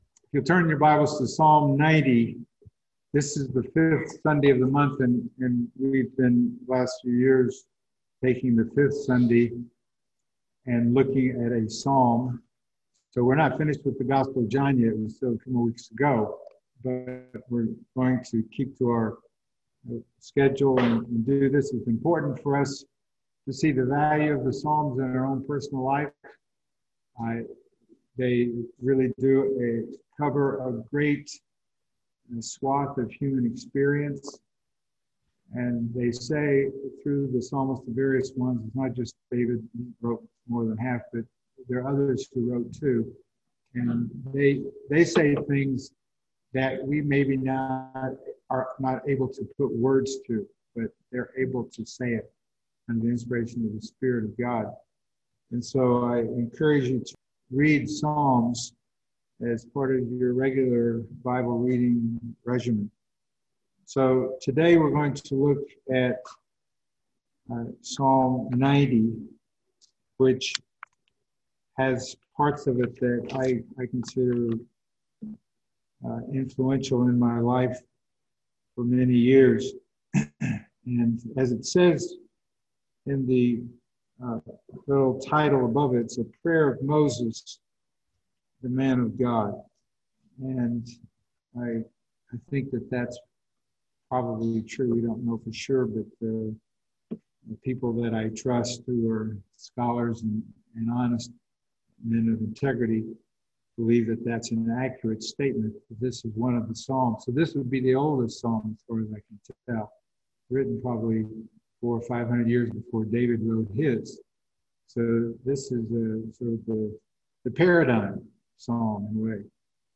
Psalm 90 Service Type: Sunday Morning Bible Text